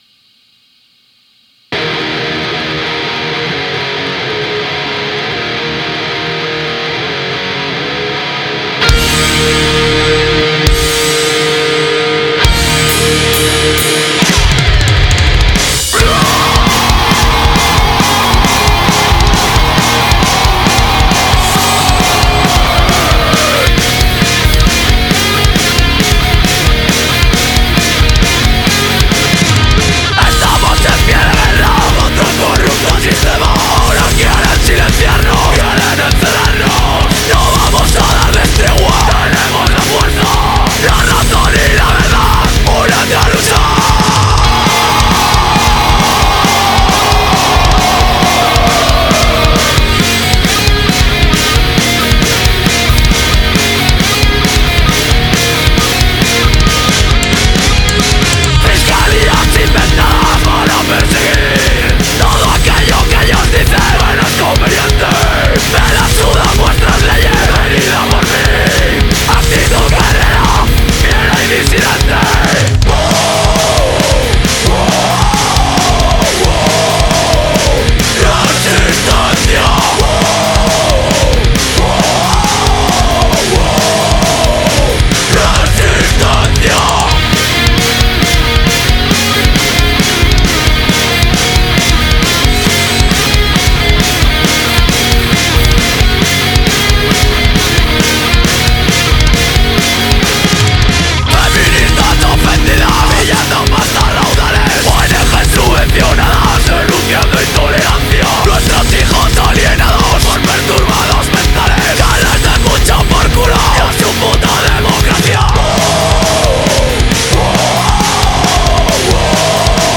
aggressiven und fast reinen Hardcore